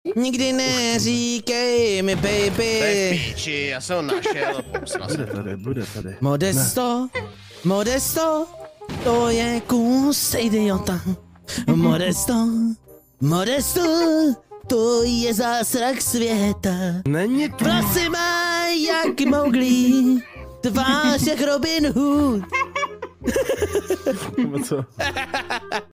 zpívá